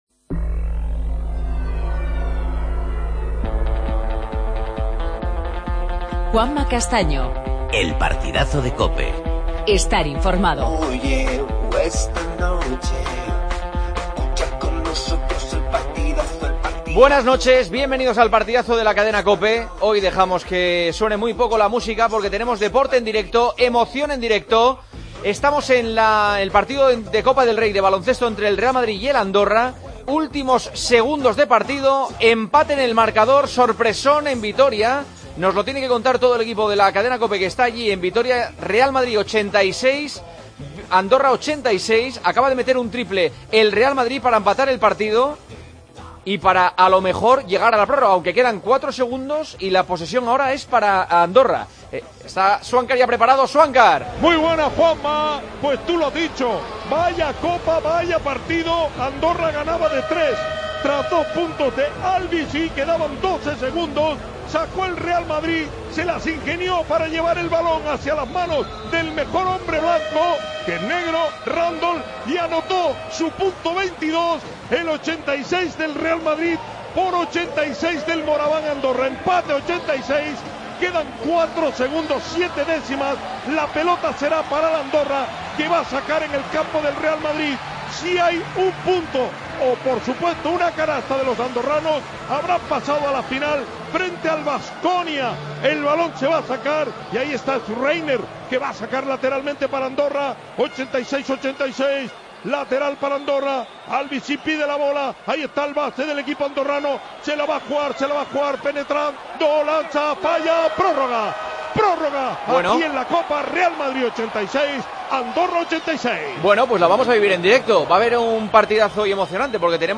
Entrevista a Nocioni: "A veces, se gana así".